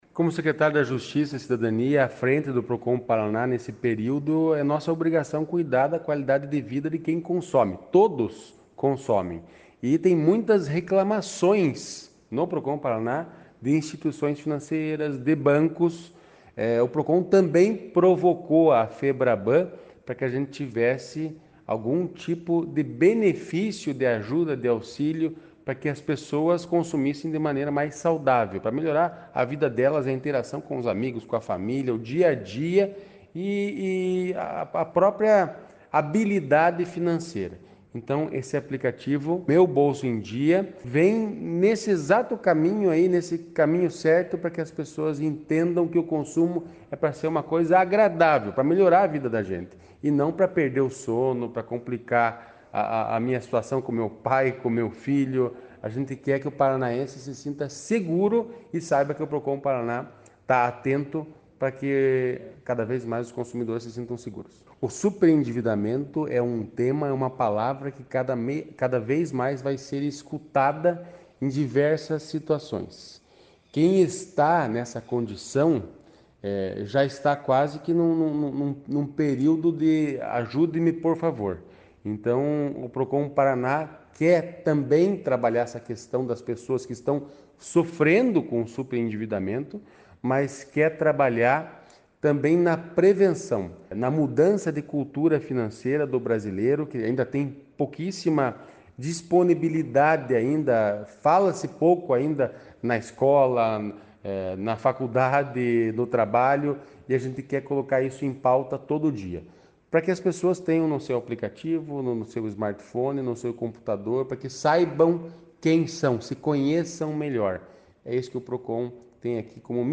Sonora do secretário da Justiça e Cidadania, Santin Roveda, sobre o uso da plataforma ‘Meu Bolso em Dia’ no Paraná